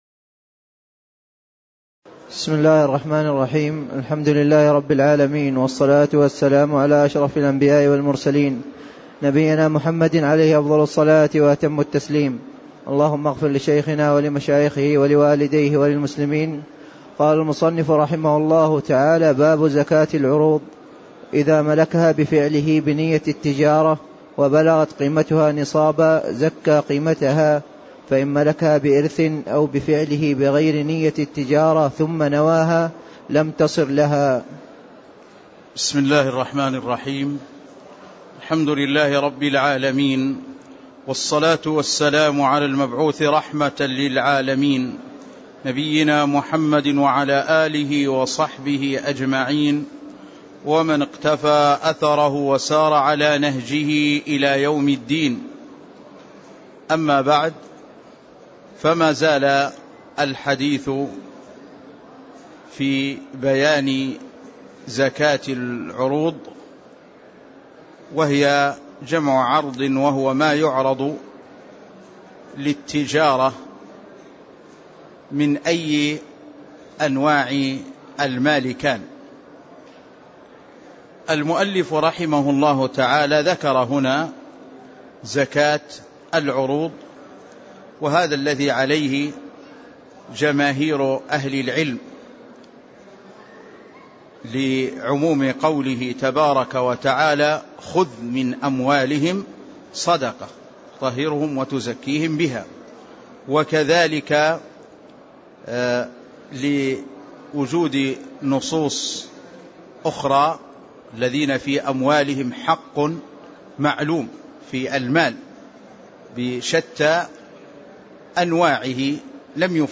تاريخ النشر ٢١ ربيع الثاني ١٤٣٦ هـ المكان: المسجد النبوي الشيخ